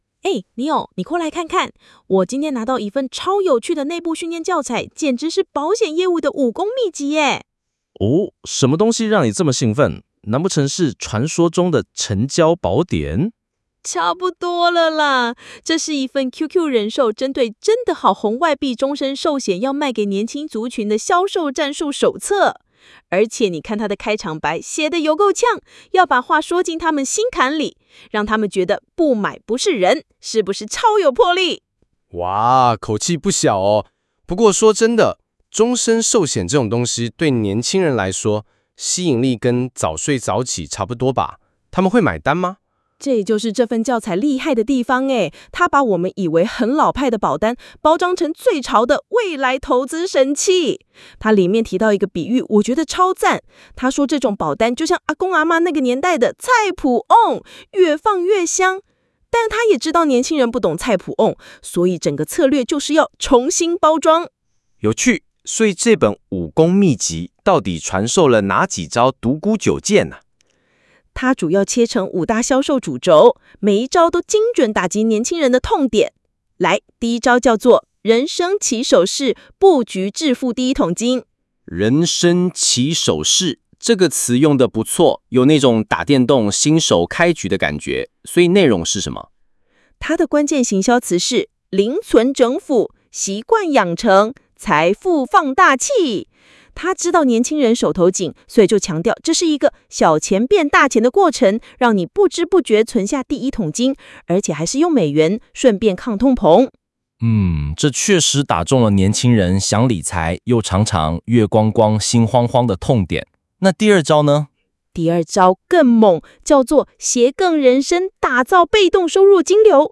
將業務手冊中的精華內容，以對談、故事或案例分享的形式錄製成音頻節目。